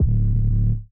Metro 808s [Trap Hood].wav